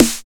13 909 SNR.wav